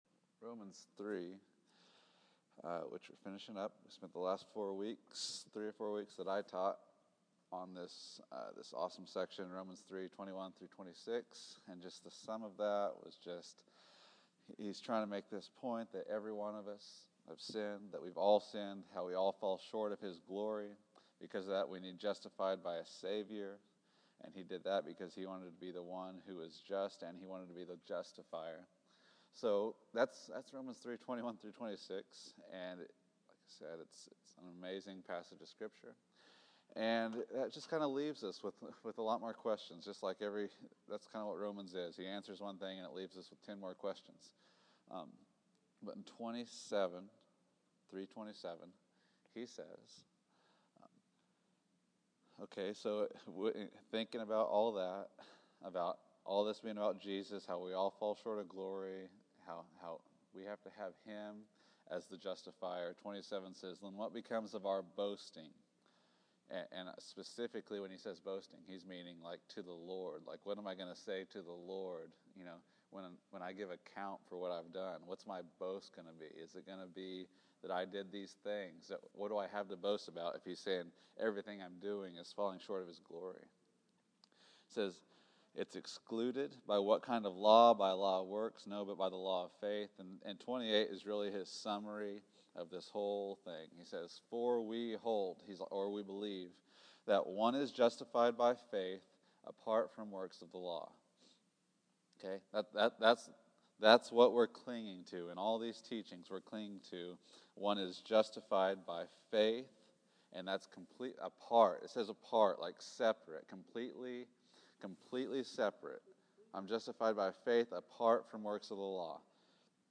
Romans 4:1-8 March 15, 2015 Category: Sunday School | Location: El Dorado Back to the Resource Library The burden of living under the law versus looking to His new mercies everyday.